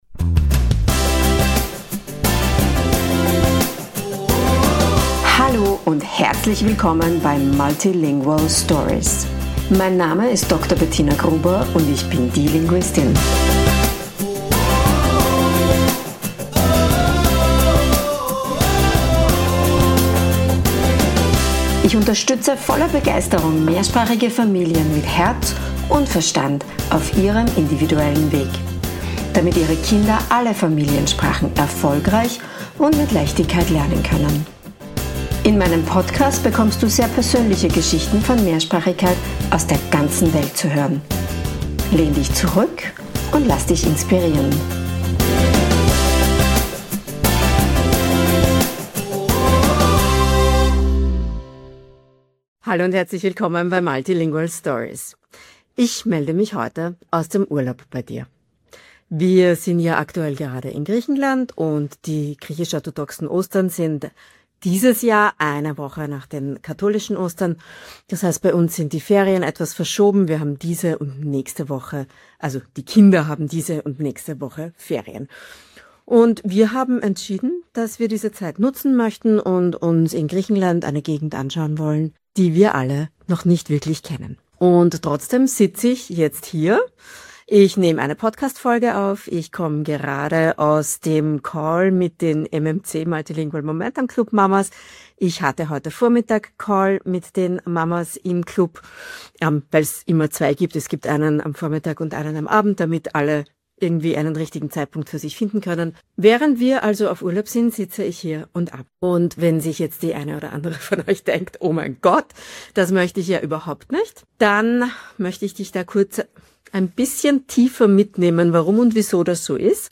Urlaub in Griechenland, meine Kinder schlafen und ich nehme eine Podcastfolge auf.